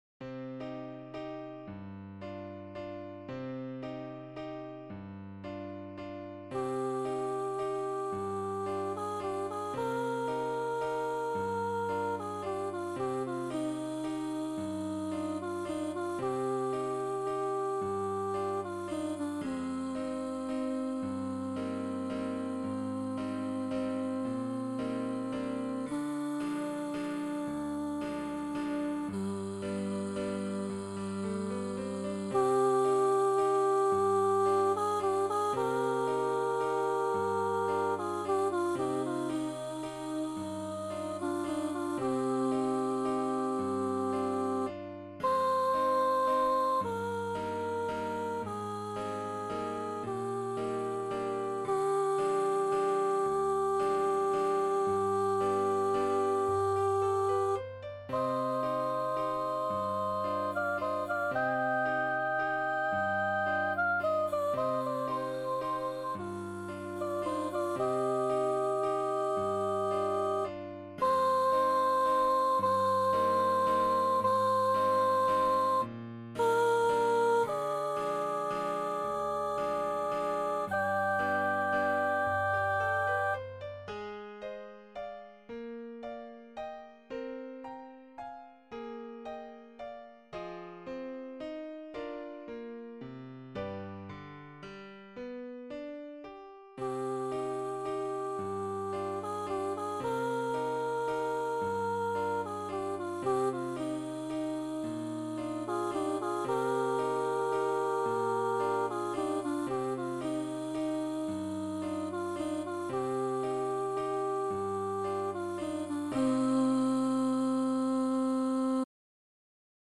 Soprano duet / piano